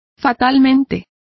Also find out how fatalmente is pronounced correctly.